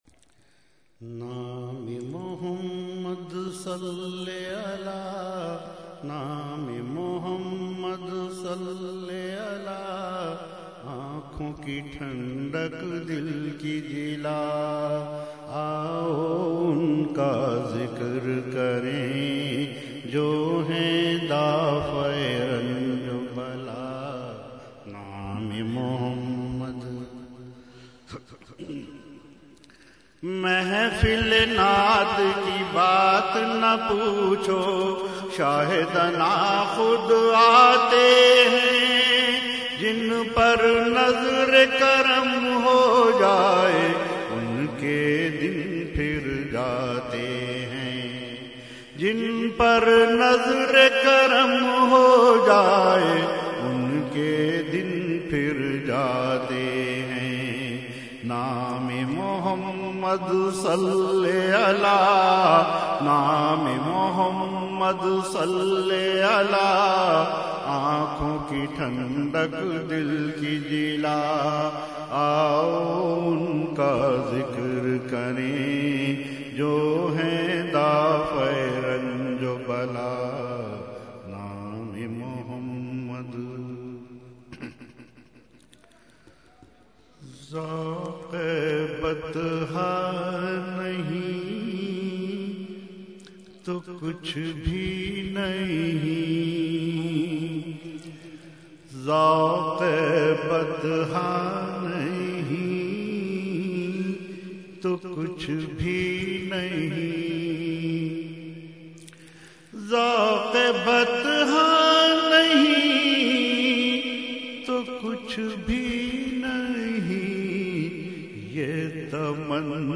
Category : Naat | Language : UrduEvent : Mehfil 11veen Nazimabad 23 March 2012